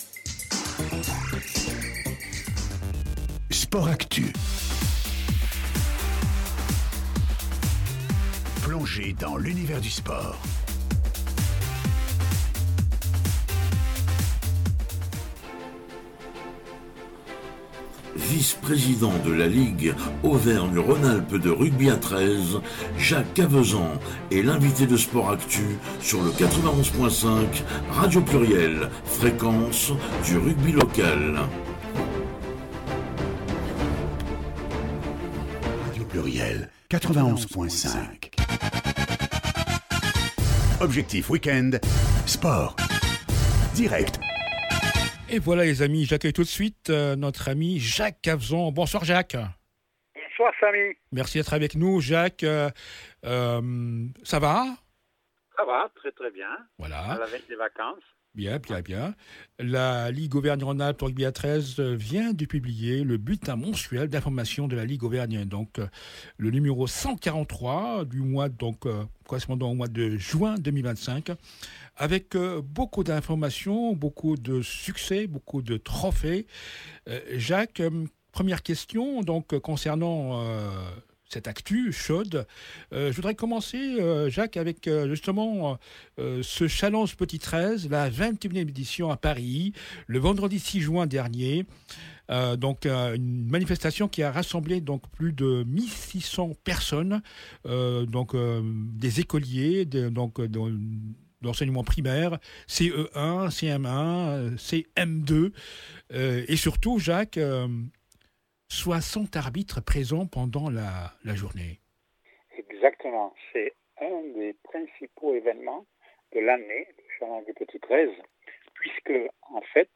L’interview du président mois de JUIN 2025